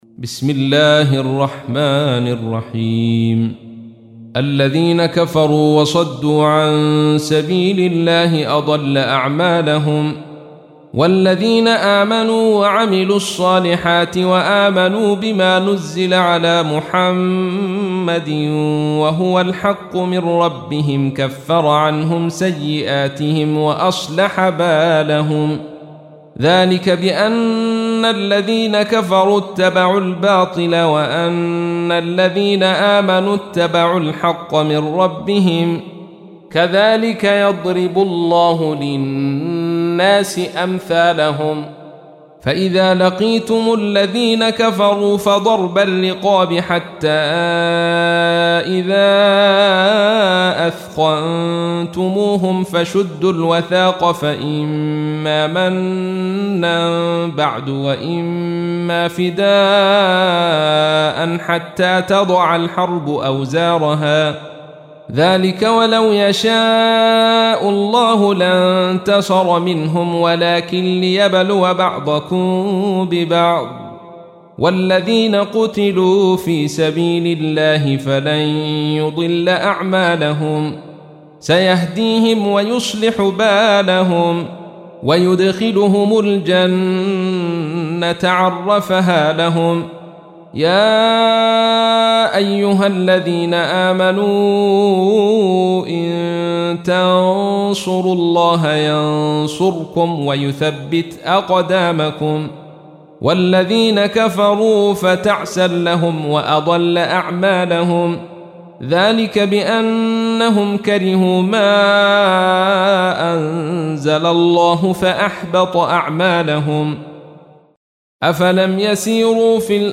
تحميل : 47. سورة محمد / القارئ عبد الرشيد صوفي / القرآن الكريم / موقع يا حسين